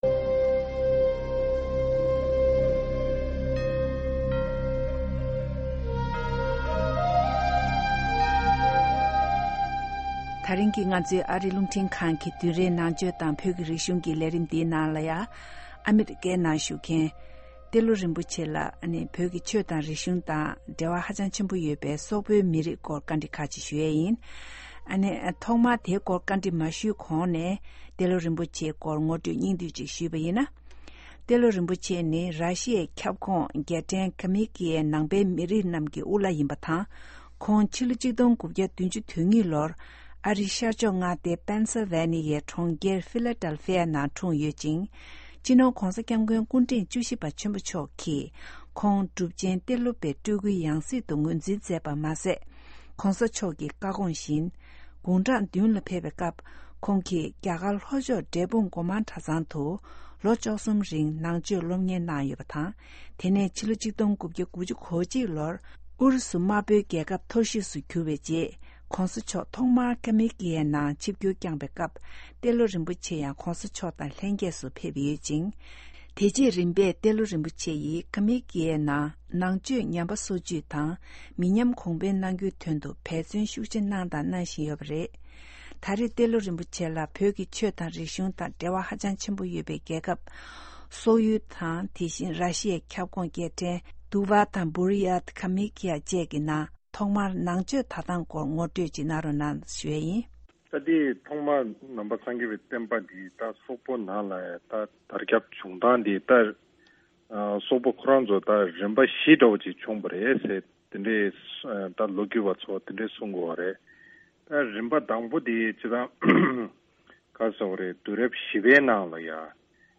interview with Telo Rinpoche, head lama of the Republic of Kalymekia, on Tibet-Mongol relations. Telo Rinpoche tells Buddhism and Culture that the Mongol-Tibet relation had begun during Gengkhiz Khan’s era when his grandson Kublai Khan became the disciple of Tibet’s Drogon Chogyal Phagpa.